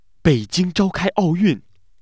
fear